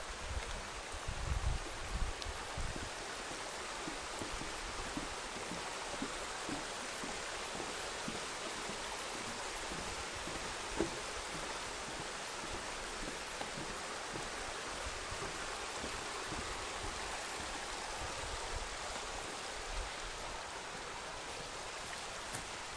Stream on Lithia Park trail